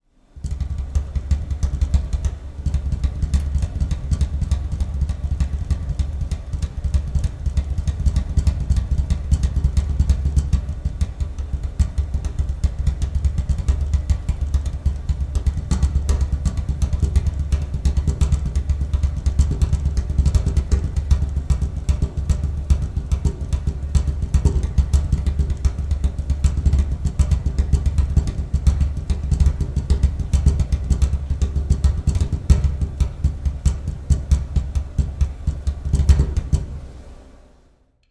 敲击2
描述：用手指敲击桌面。
Tag: 韵律 丝锥 攻丝